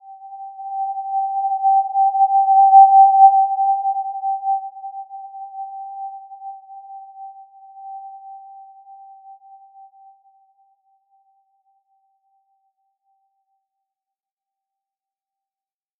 Simple-Glow-G5-mf.wav